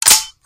rogue_shoot_empty.ogg